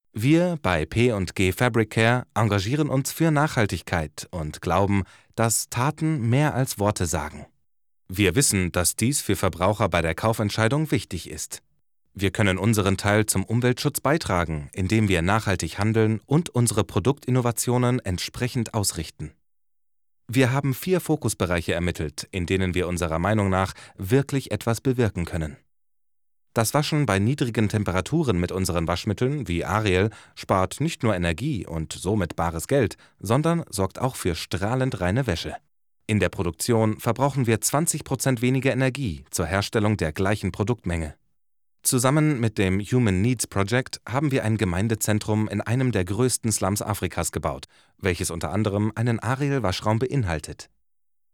Warm, dynamic, friendly, versatile, corporate, commercial and multilingual fresh voice to make your production shine!
Sprechprobe: Industrie (Muttersprache):